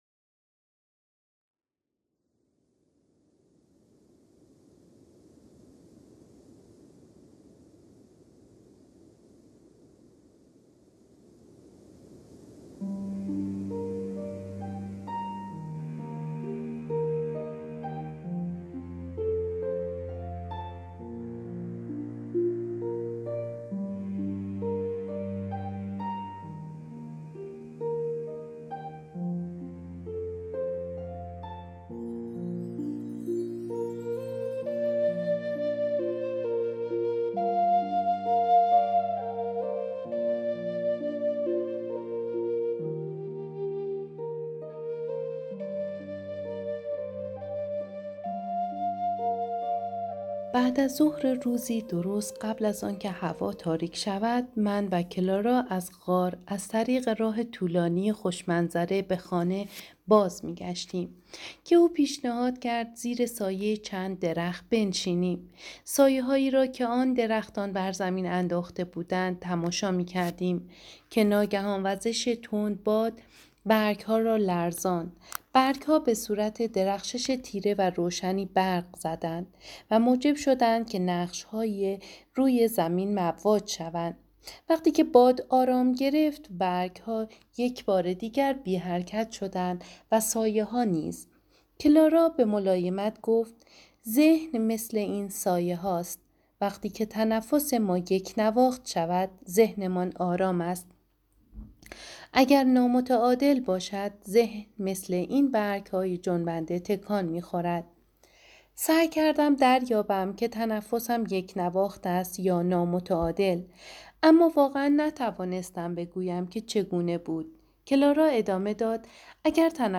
گل مهدی طارمی برای المپیاکوس در جام حذفی یونان / فیلم برچسب‌ها: كتاب صوتی گذر ساحران از آموزه هاي دون خوان دیدگاه‌ها (اولین دیدگاه را بنویسید) برای ارسال دیدگاه وارد شوید.